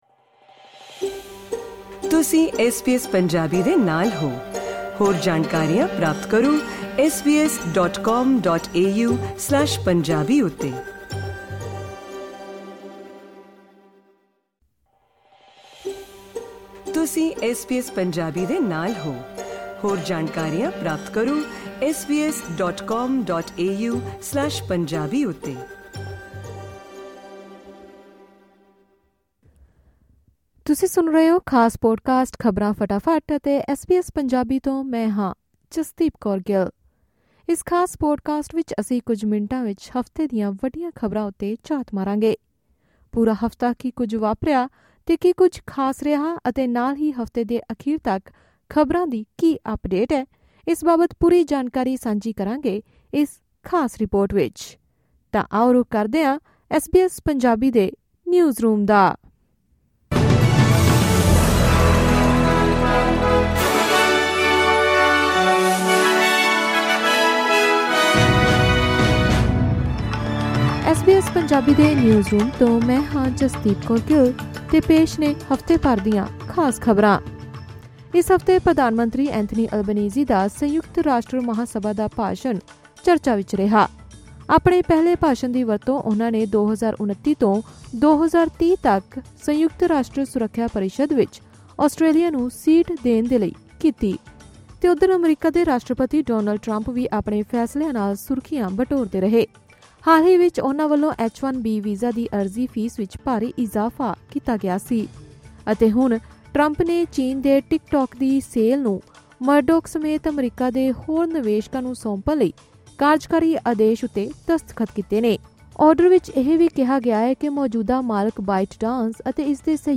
Weekly News Bulletin.